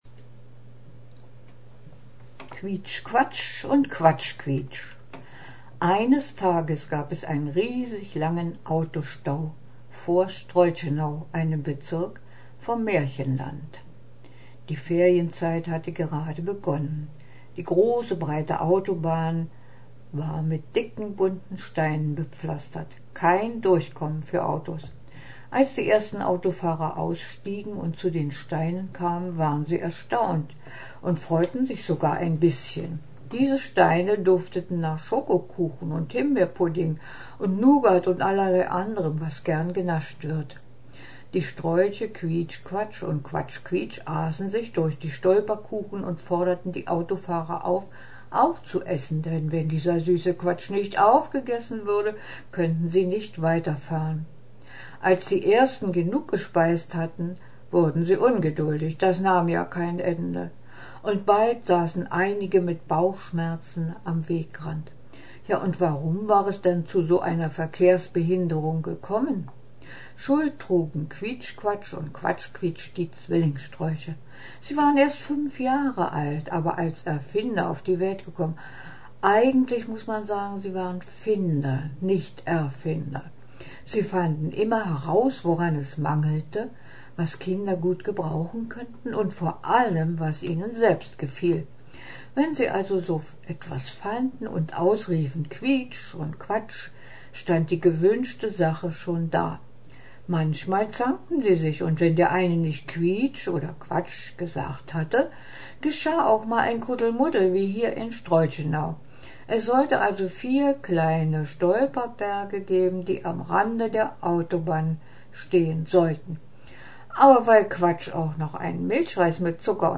ausgedacht, aufgeschrieben und gesprochen